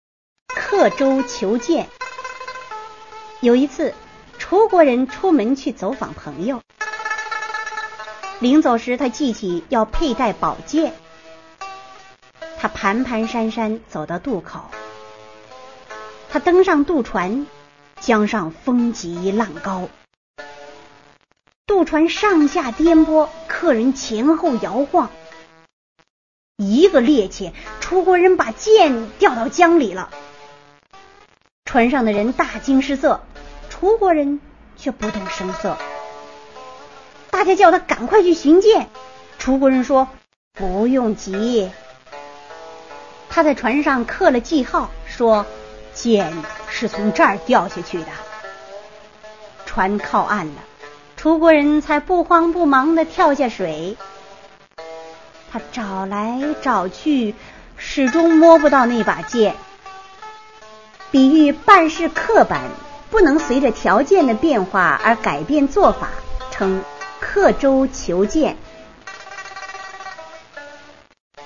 《刻舟求剑》原文、译文、朗读